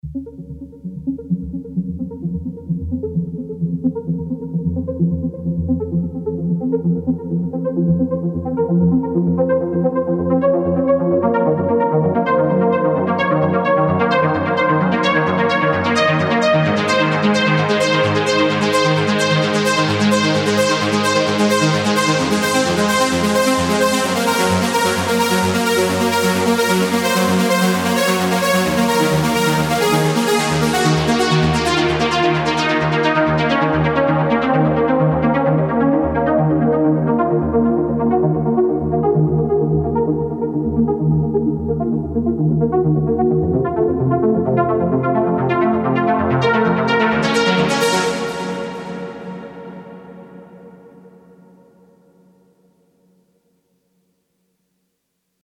Ich habe jetzt etwas mit der Diva rumprobiert.
Nicht ganz so bissig wie das Original und auch schlecht EQed, also da müsste ich noch ran Hatte bisher im Mix immer mit der Diva zu kämpfen ...